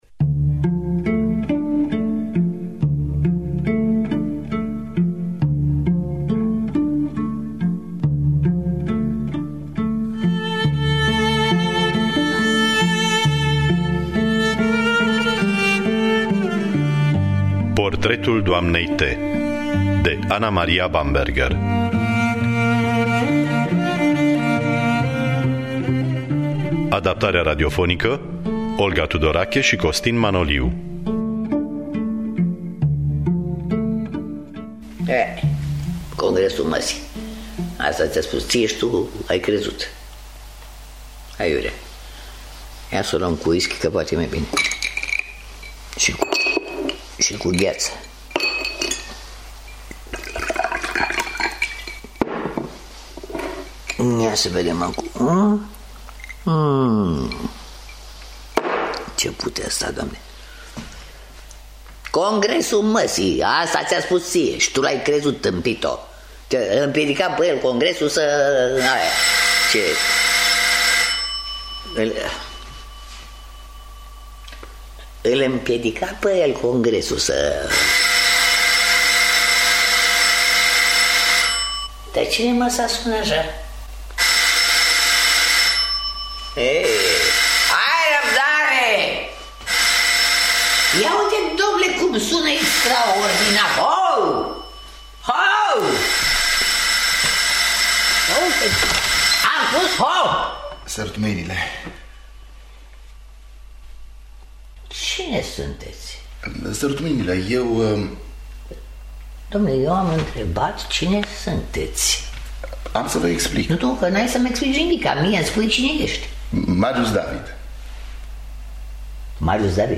În distribuţie: Olga Tudorache şi Marius Bodochi.